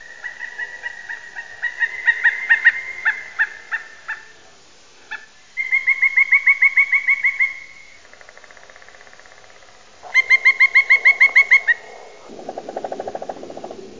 Самцы издают крик и стучат громко по дереву с целью привлечения самок, их крик слышен на небольшом расстоянии.